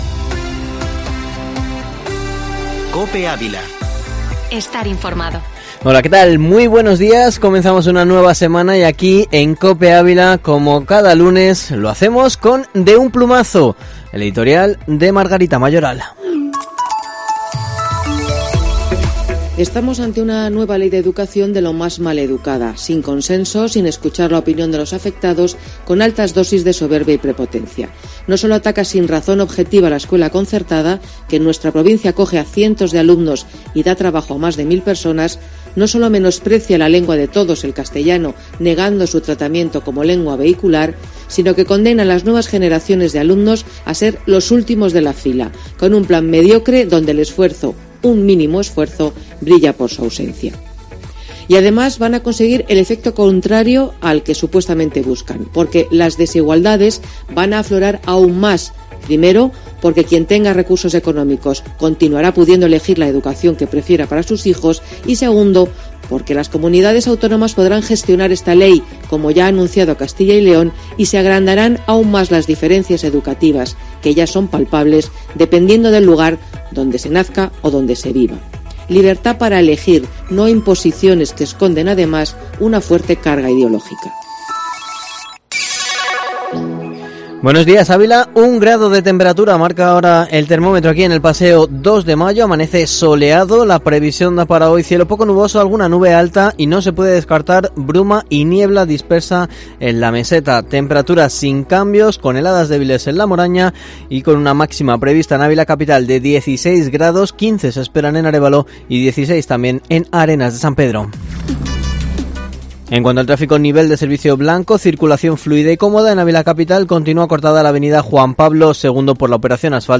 Informativo matinal Herrera en COPE Ávila 23/11/2020